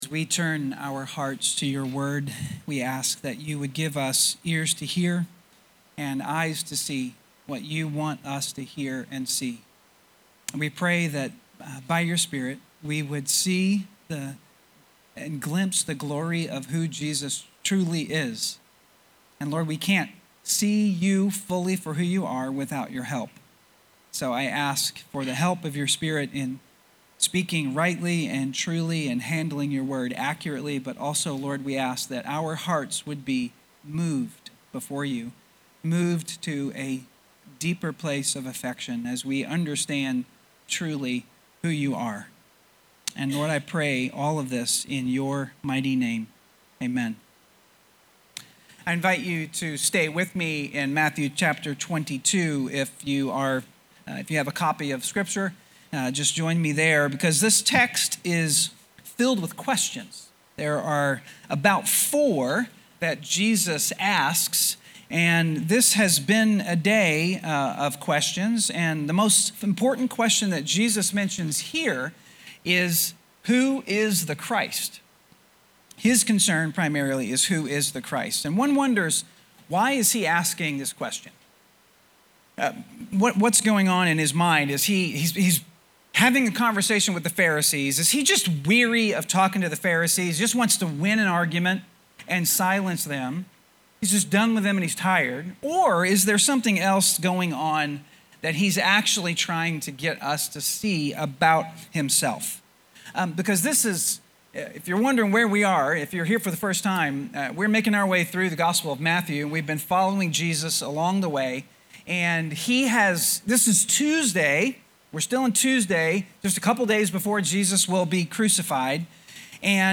Sermons | Hope Christian Church